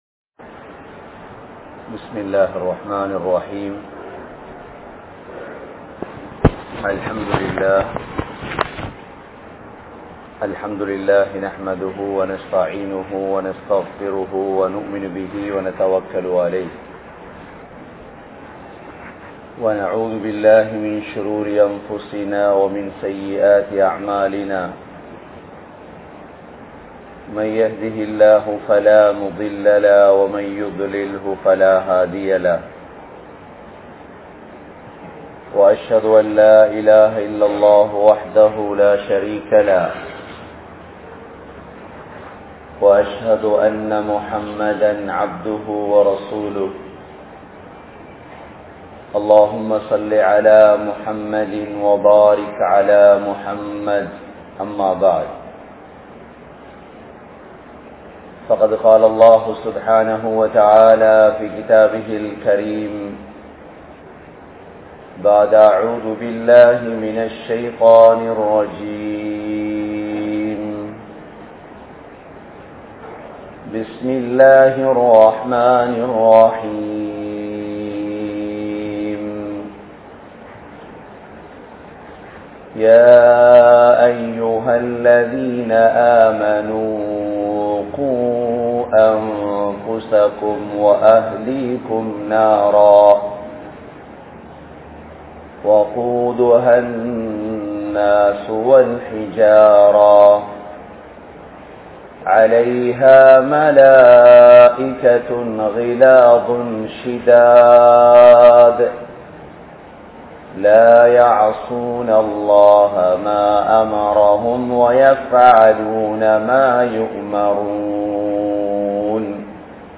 Pen Pillaihalai Valarkum Murai (பெண் பிள்ளைகளை வளர்க்கும் முறை) | Audio Bayans | All Ceylon Muslim Youth Community | Addalaichenai
Matara, Muhiyadeen Jumua Masjith